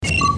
main click
Category: Sound FX   Right: Personal